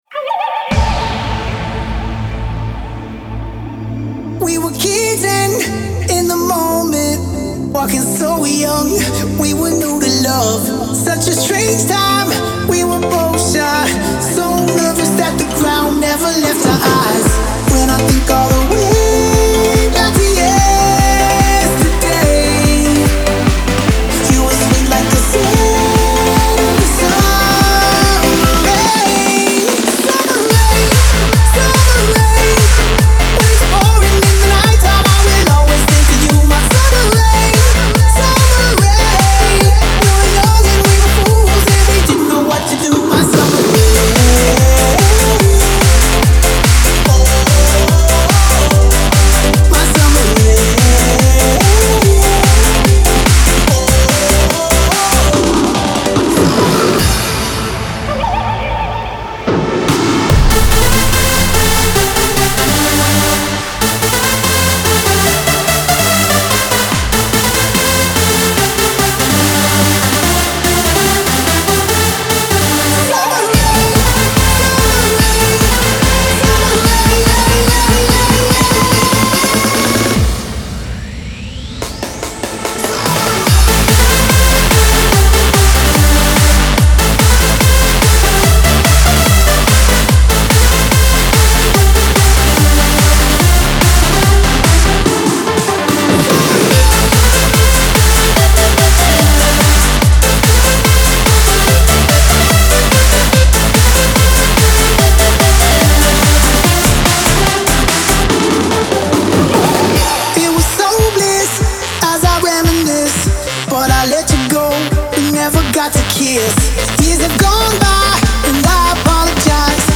Genre: House, Trance, Electronic, Dance.